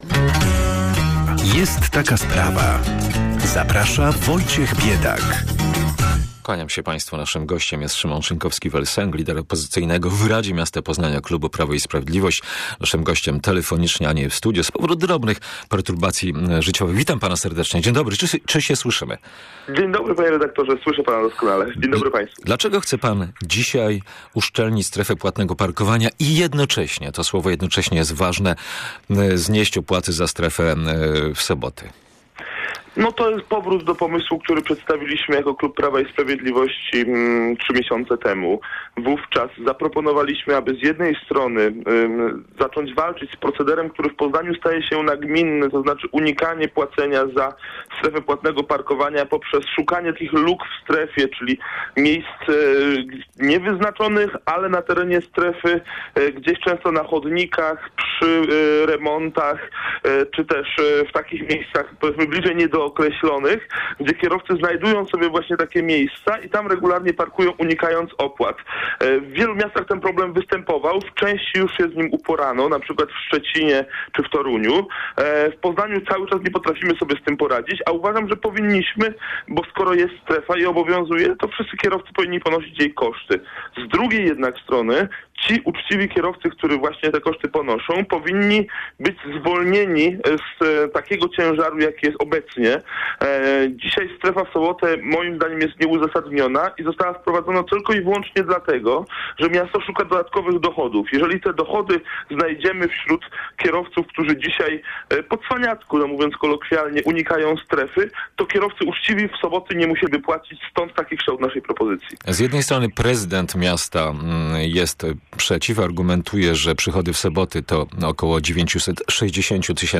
Dyskusja o kontrowersyjnym pomniku